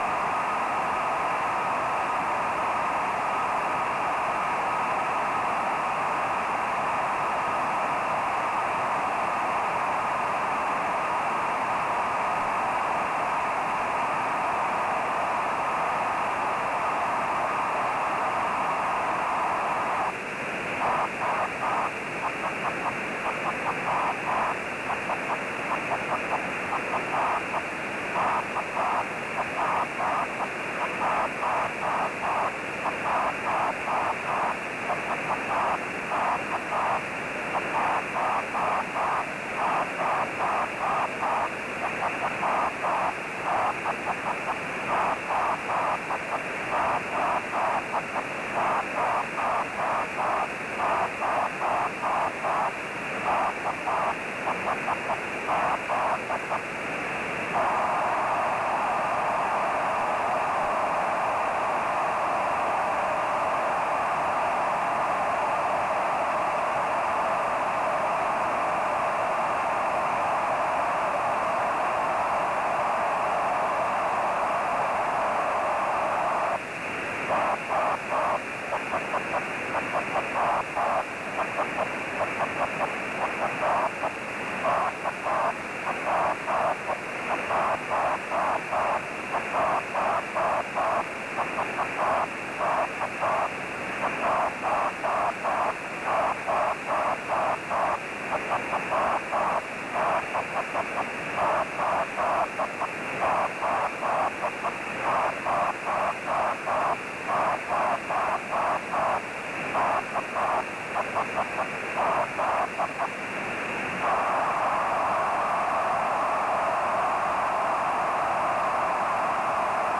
- the CW ID of the beacon causes the receiver AGC to pump accordingly (for these recordings it was not possible to defeat the receiver AGC)
Rain Scatter recordings of the OH3SHF beacon:
simultaneous Rain Backscatter and Tropospheric Forward Scatter Enhancement (25.08.2005, 2233 Local Time)